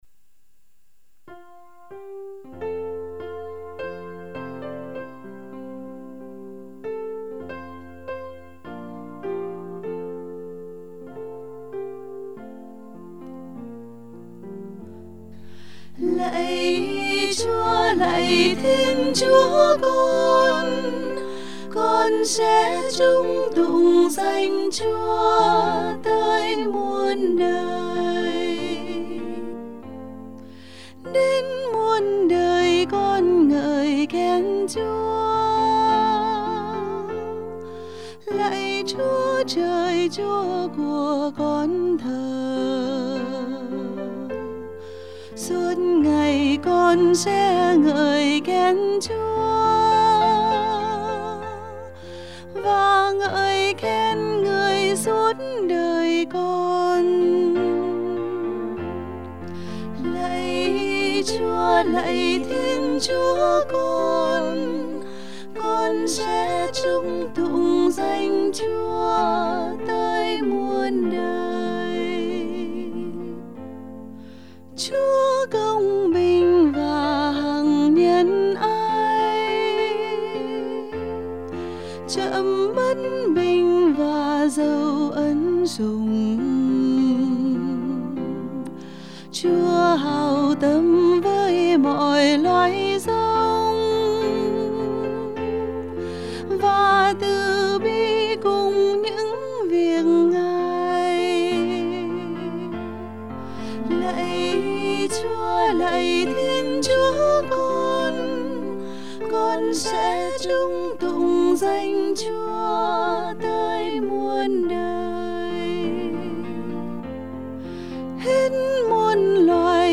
Sunday 7/5/2020 - Chúa Nhật 14 TN - 4th of July Celebration